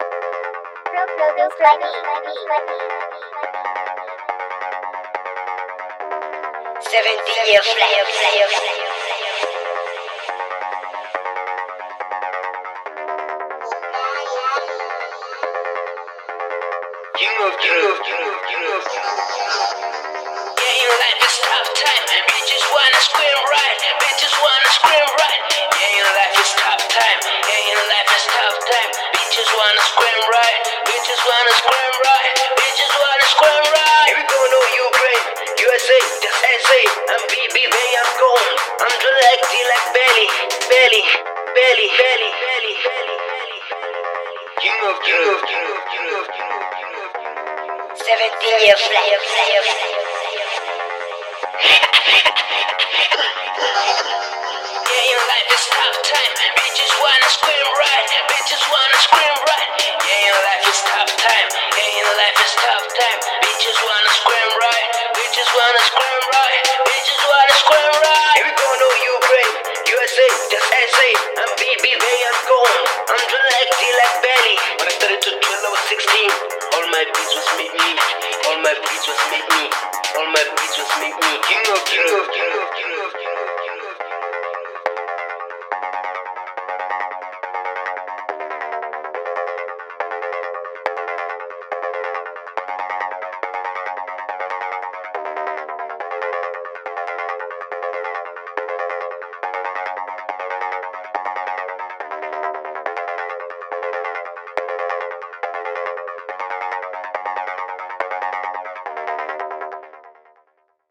hiphopdrill